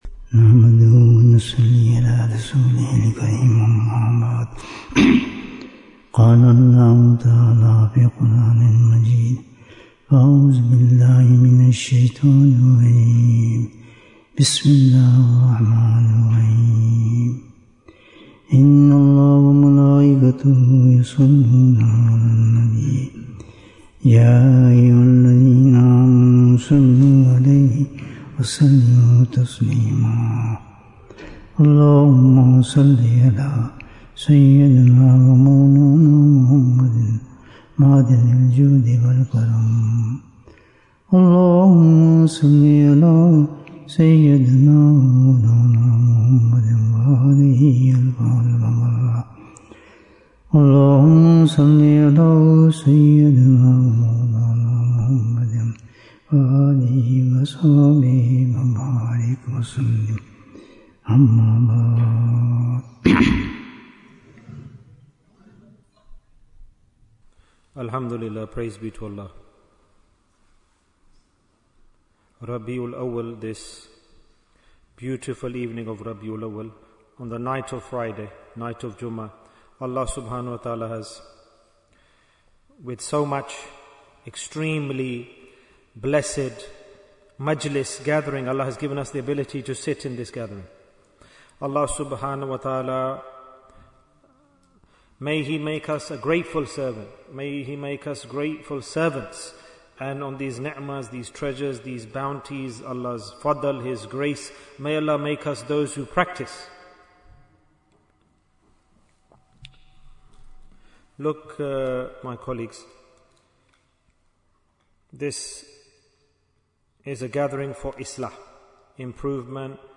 What is the Value of Time? Bayan, 77 minutes11th September, 2025